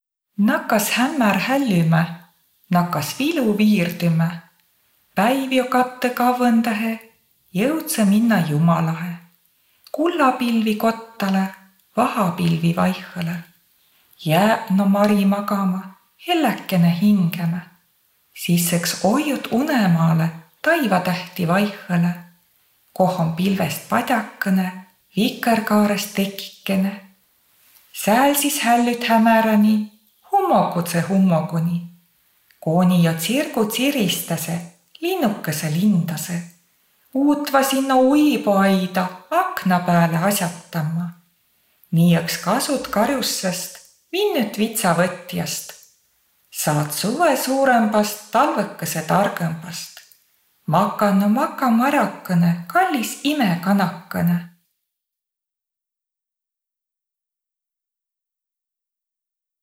Luulõtus_Hällülaul.wav